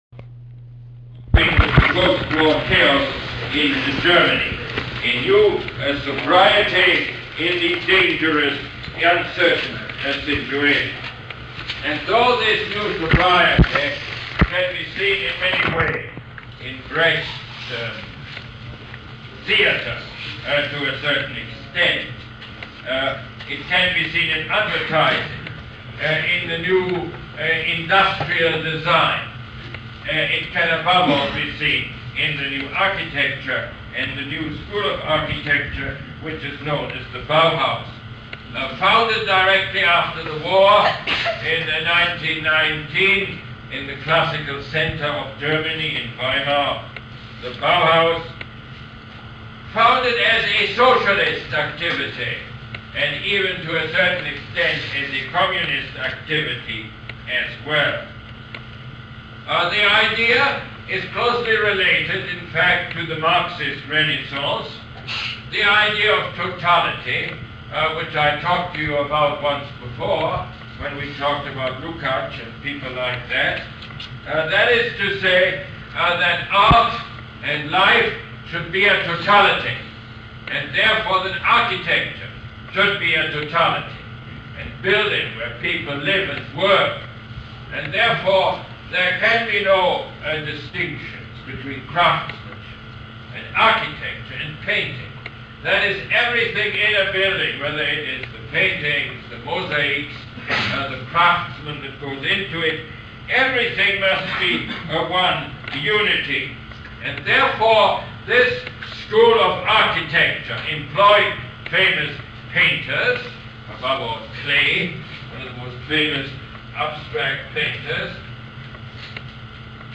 Lecture #24 - November 28, 1979